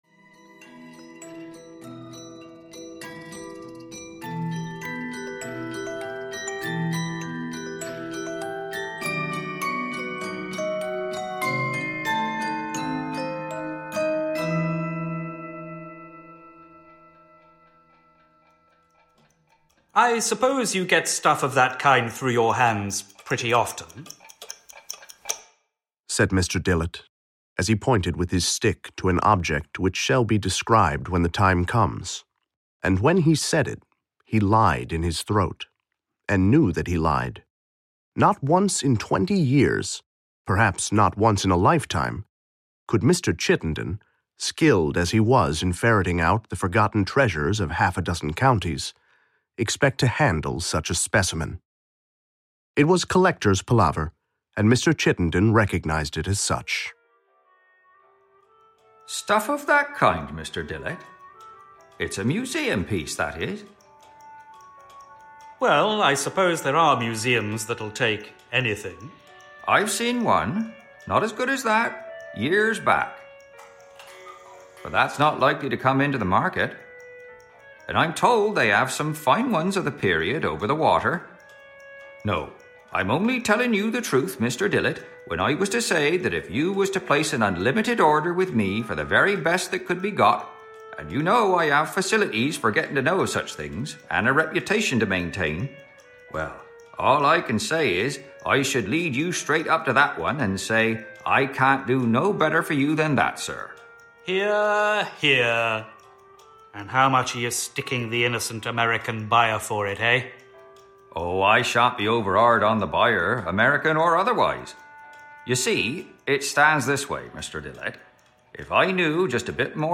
Ukázka z knihy
The atmosphere of horror and mystery is underlined by excellent performances by all actors and music as well as by the original graphic design of the cover.